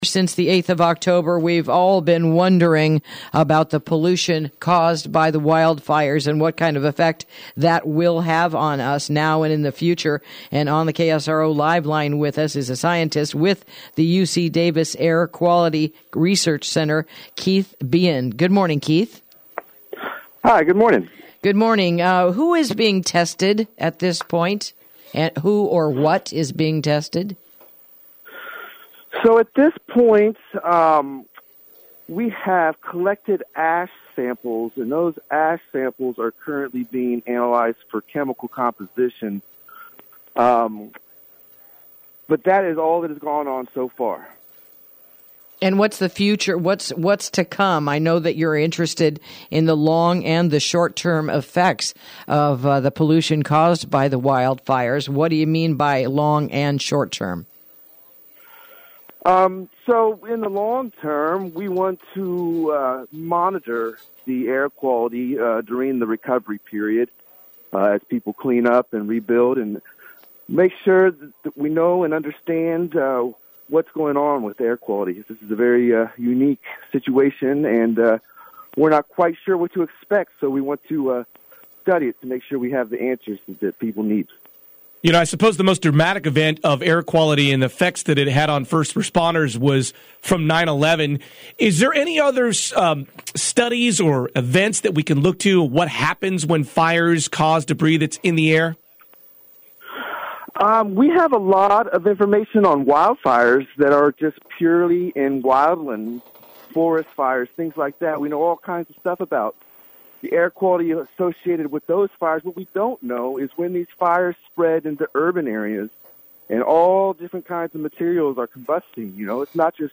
Interview: UC Davis Study on Post Fire Pollution